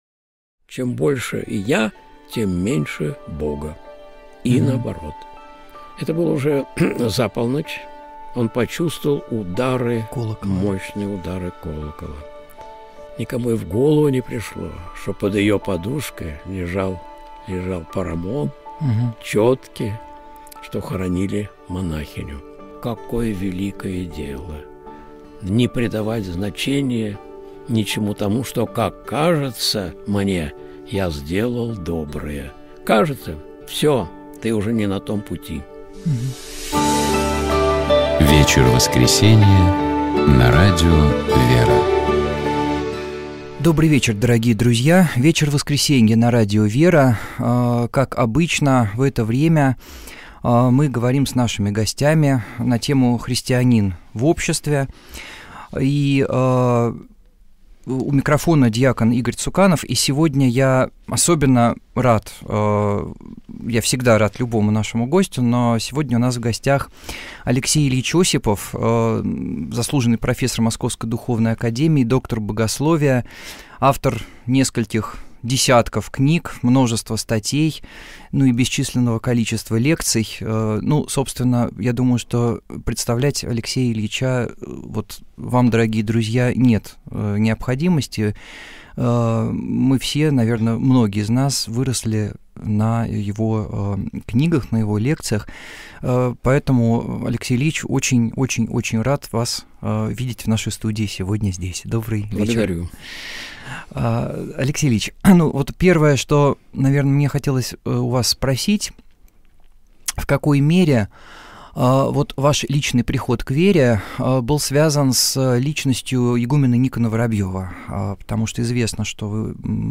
Интервью на радио «Вера» (21.05.2023)